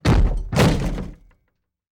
DoorBreaksBreakDow FS022601.wav